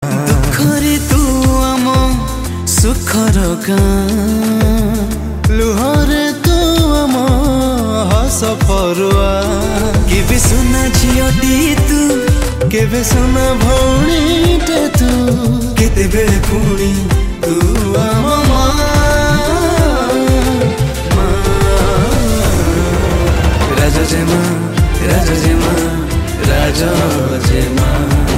Odia Ringtones
love song